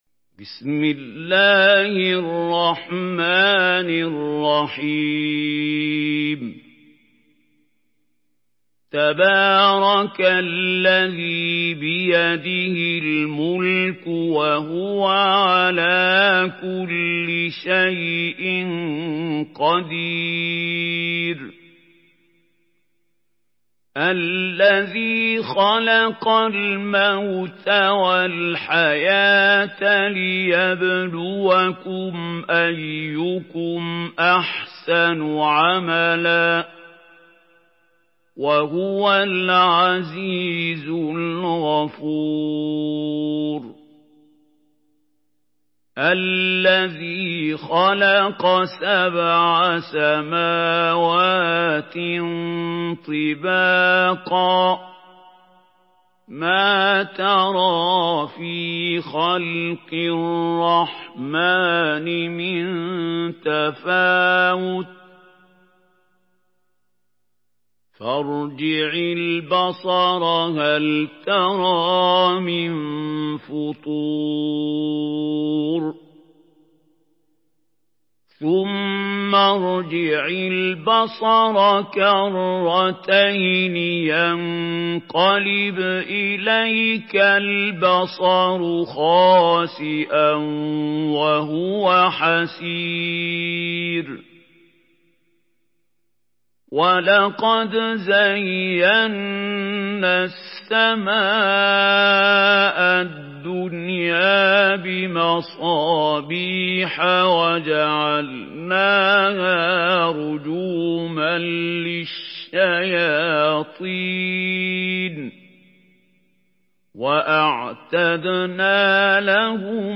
Surah الملك MP3 in the Voice of محمود خليل الحصري in حفص Narration
Listen and download the full recitation in MP3 format via direct and fast links in multiple qualities to your mobile phone.